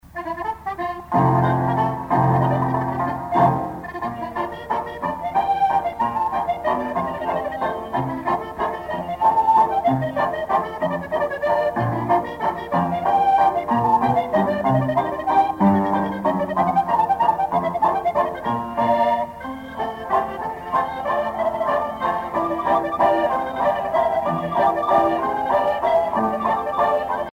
danse : java
Pièce musicale éditée